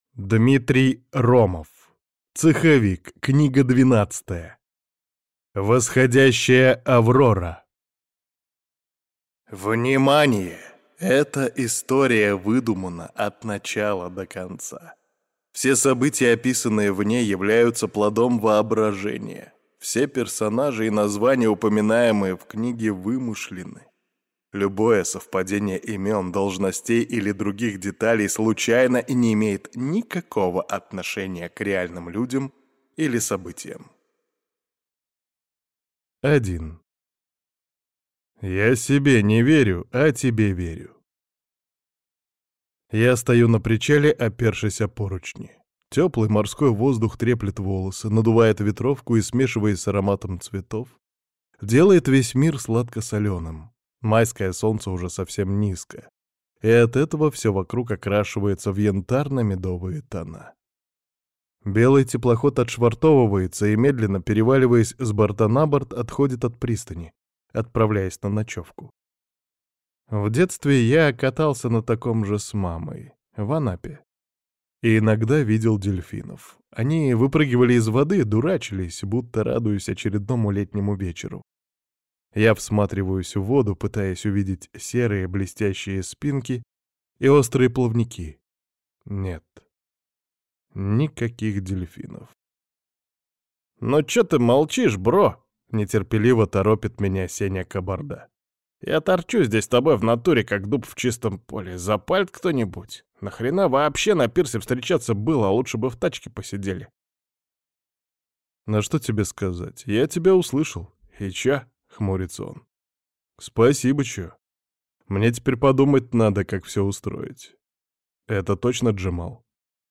Слушать аудиокнигу Адаптация совести полностью